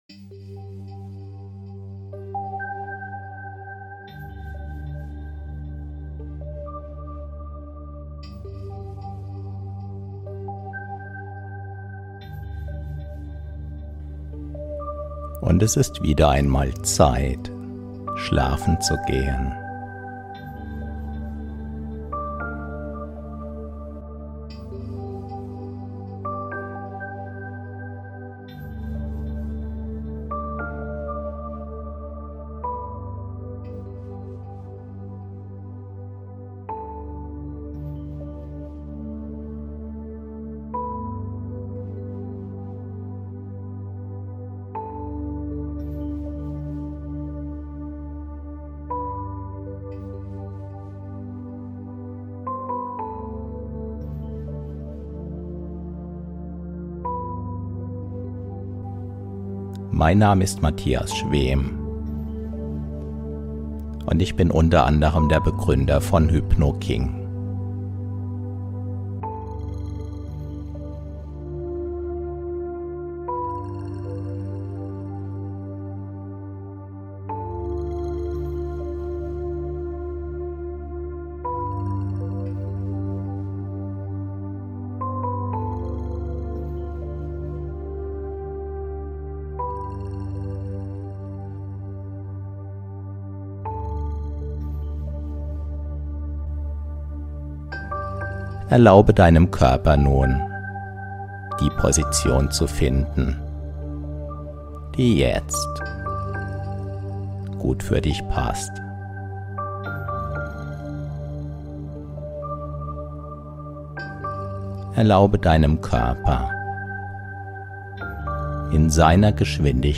Erlebe die hypnotische Magie einer Live-Aufnahme, die dich sanft und sicher in den Tiefschlaf führt.